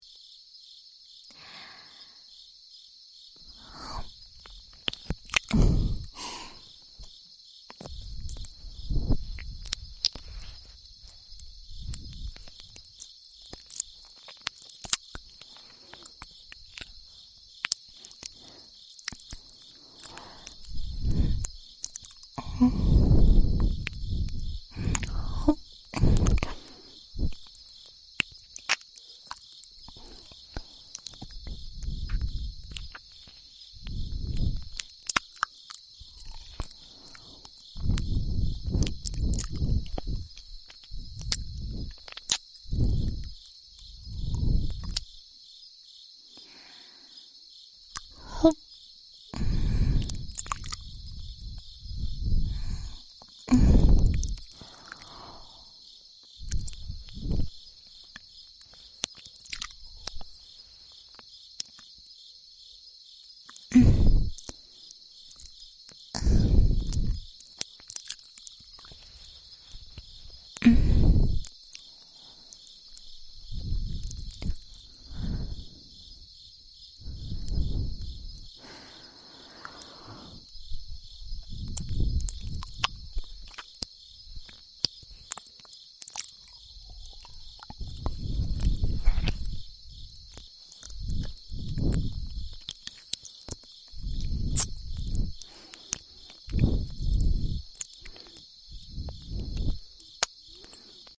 听ASMR最常见的体验，就是姑娘们一边给你舔耳朵，一边在你耳边告诉你：